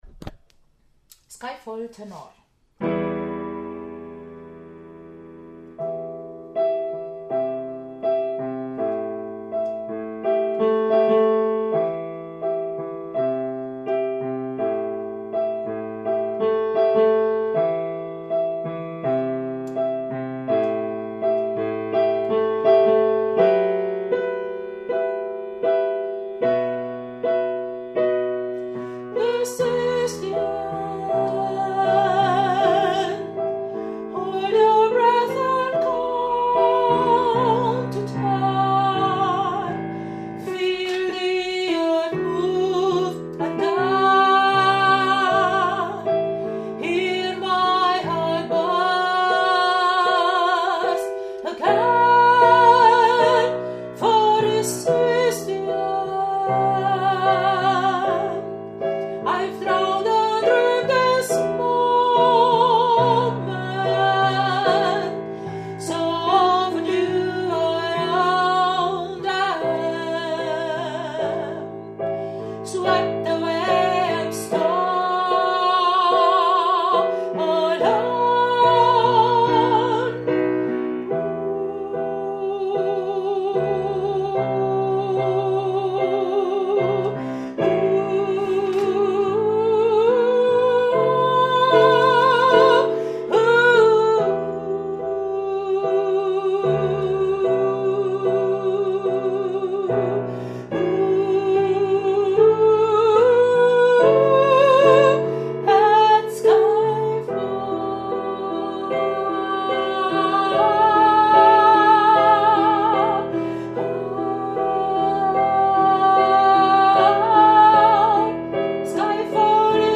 skyfall-Tenor.mp3